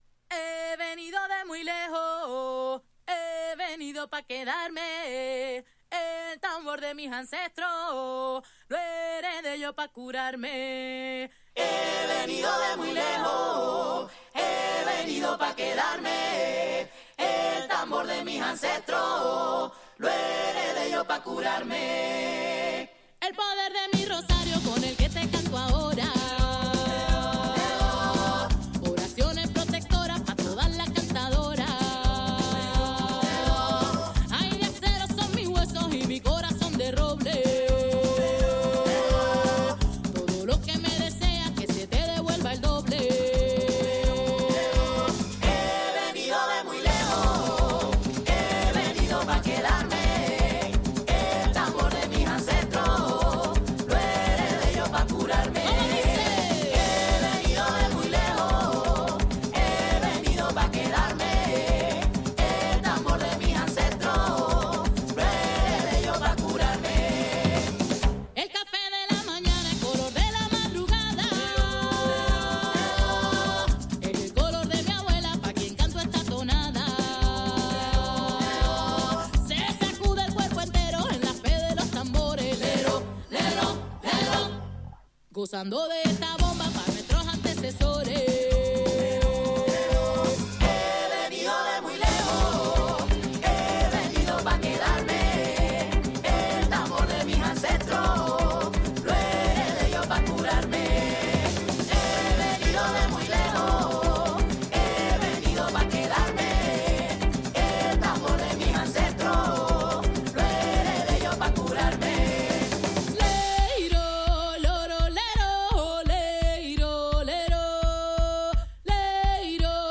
Tags: Tropical , Spain